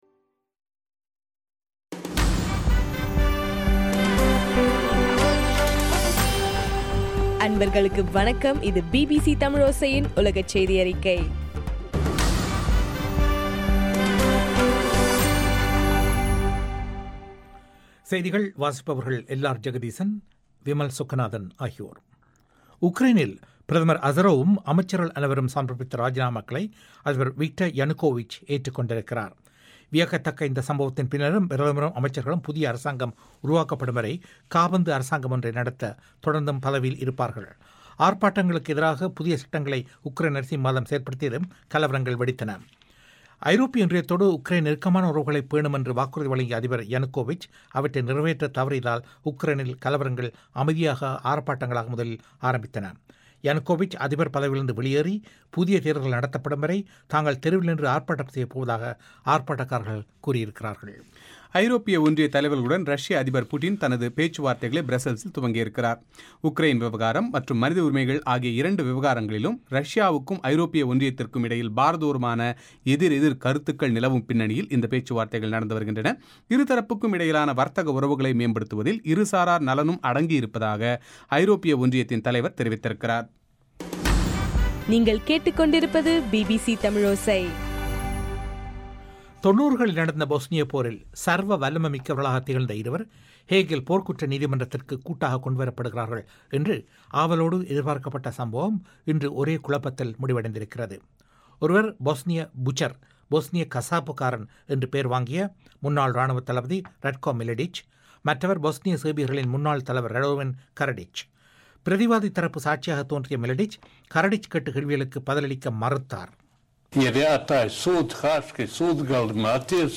ஜனவரி 28 பிபிசியின் உலகச் செய்திகள்